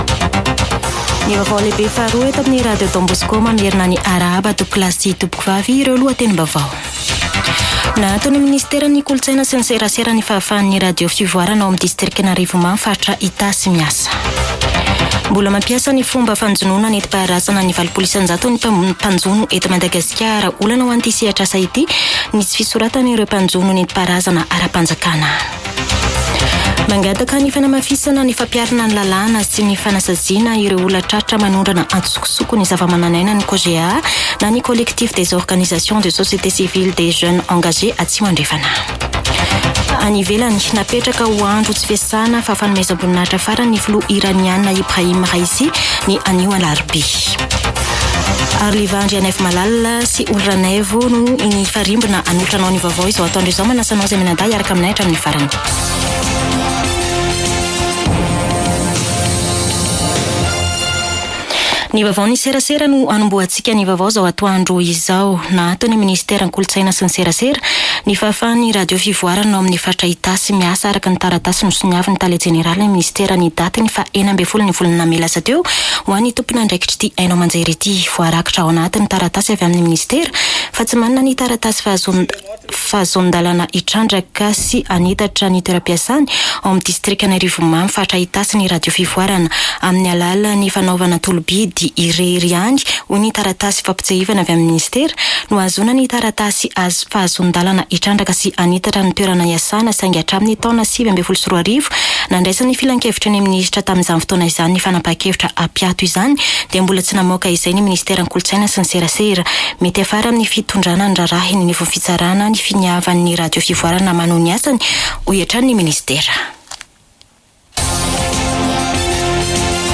[Vaovao antoandro] Alarobia 22 mey 2024